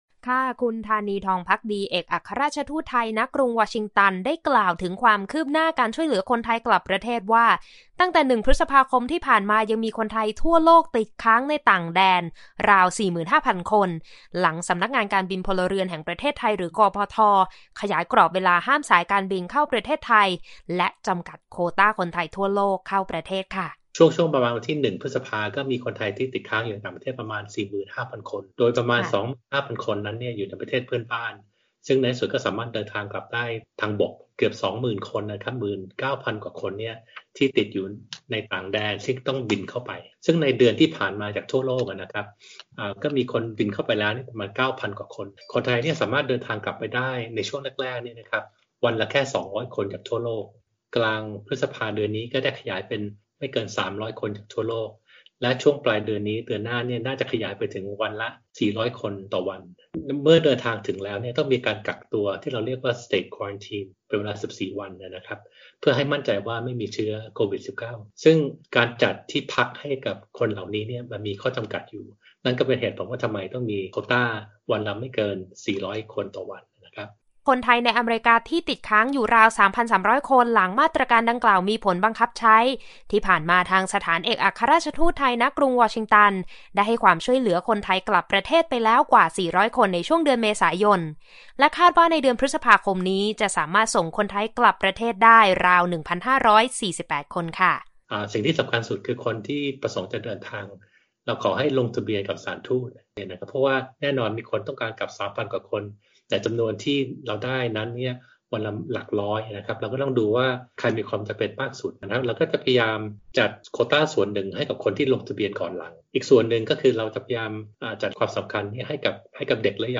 นายธานี ทองภักดี เอกอัครราชทูตไทย ณ กรุงวอชิงตัน กล่าวถึงความคืบหน้าการช่วยเหลือคนไทยกลับประเทศว่า ตั้งแต่ 1 พฤษภาคมที่ผ่านมา ยังมีคนไทยทั่วโลกติดค้างในต่างแดนราว 45,000 คน หลังทางสำนักงานการบินพลเรือนแห่งประเทศไทย หรือ กพท. ได้ขยายกรอบเวลาห้ามสายการบินเข้าประเทศไทย และการจำกัดโควต้าคนไทยทั่วโลกเข้าประเทศ